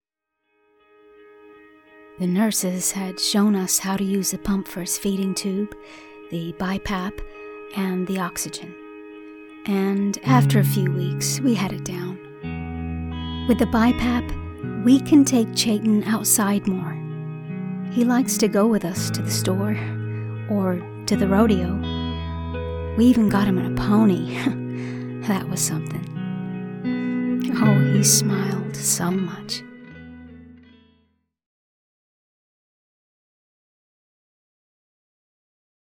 Female
Medical Narrations
American Emotive Ad Caring Soft